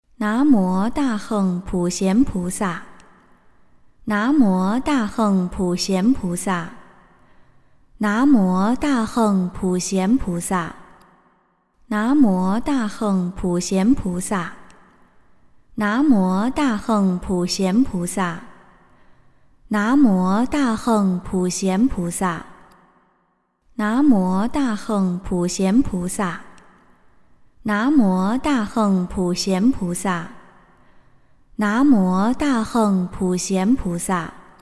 普贤菩萨（念诵） - 诵经 - 云佛论坛
普贤菩萨（念诵）